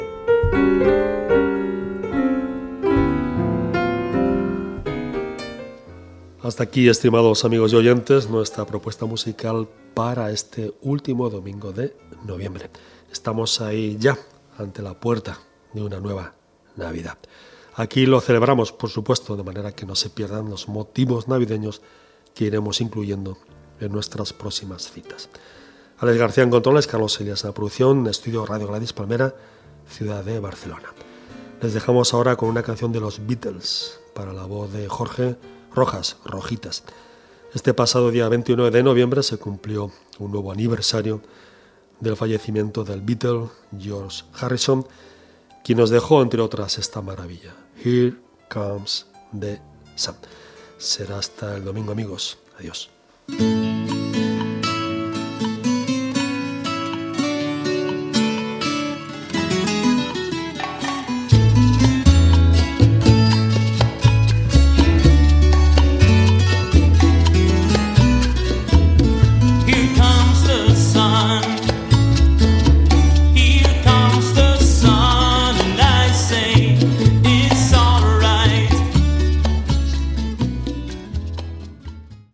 Llatina
Tema musical, comentari, comiat, equip i tema musical
Musical